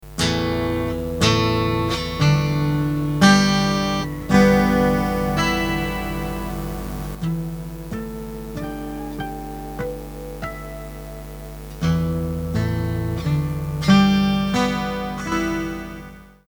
12-string guitar tuning demonstration
6-12-string-tuning.mp3